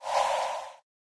swipe.ogg